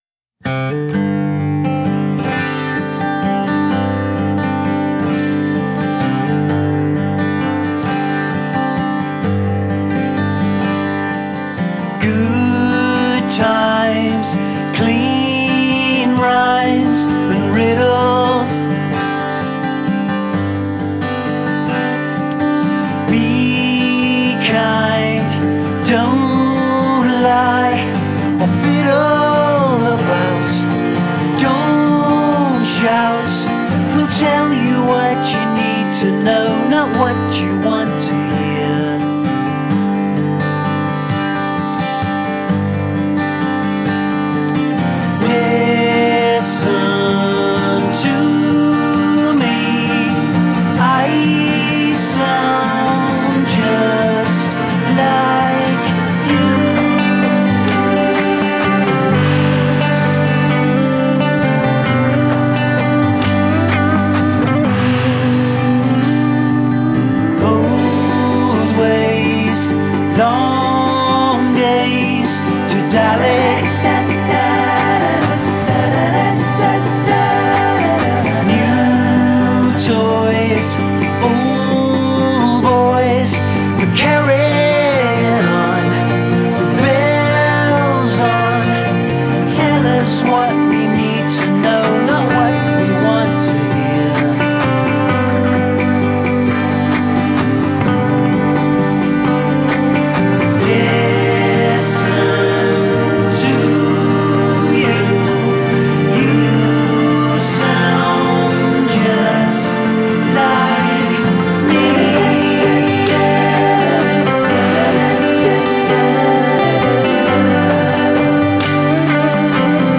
released a wistful poppy-folk/folky-pop EP in October.